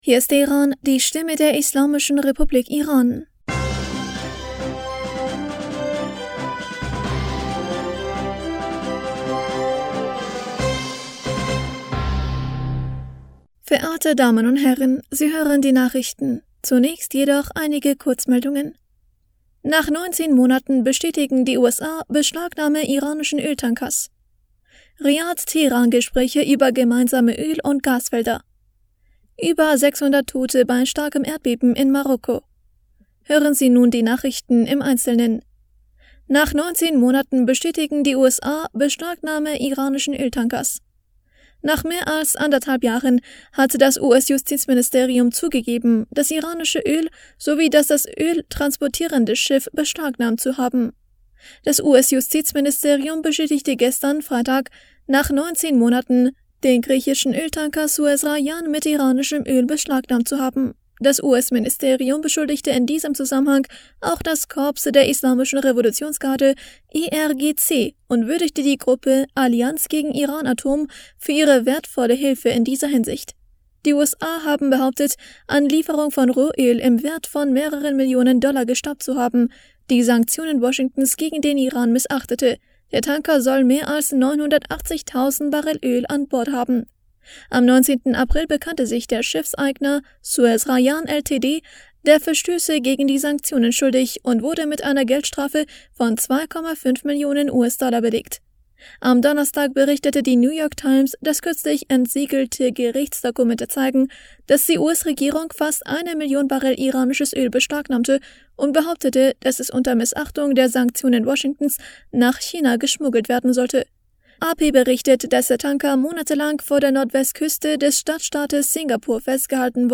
Nachrichten vom 09. September 2023